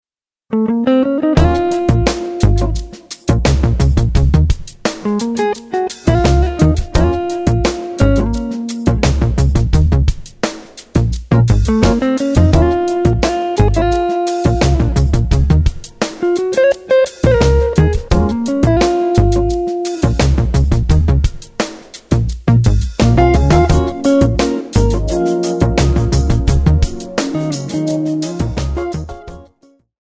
This is jazz a beautiful Album.